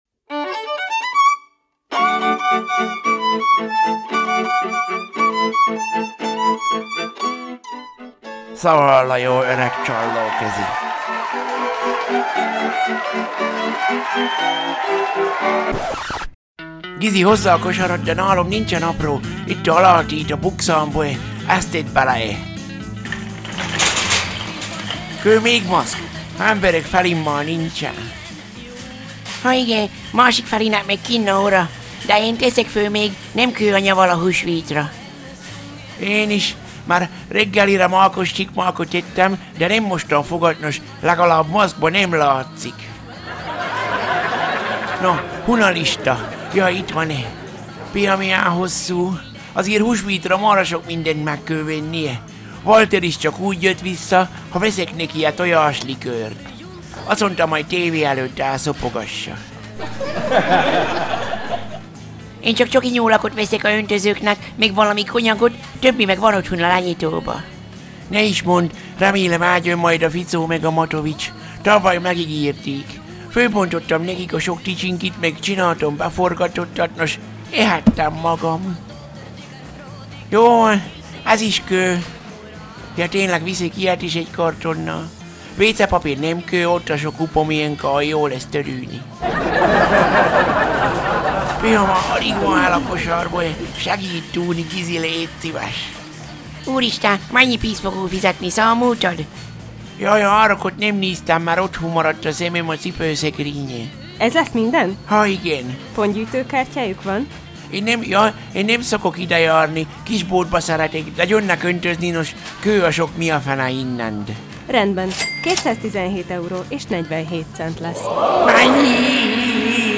Szaval